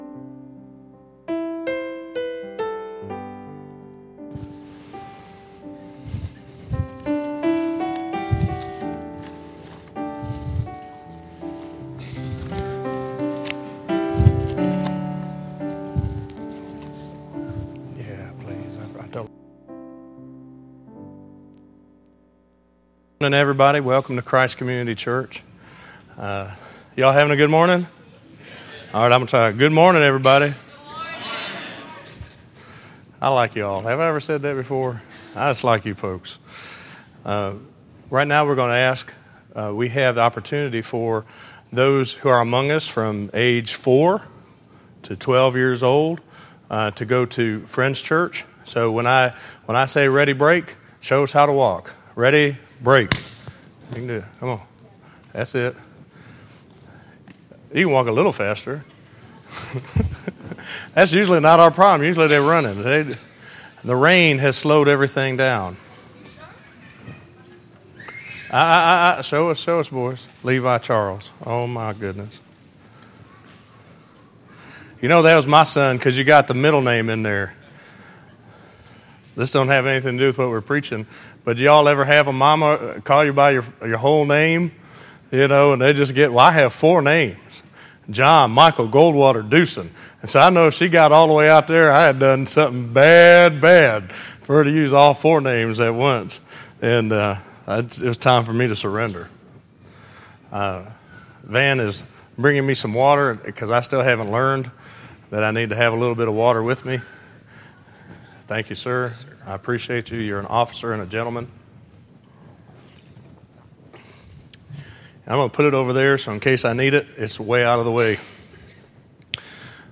Listen to Have You Been to the Empty Tomb - 04_27_2014_Sermon.mp3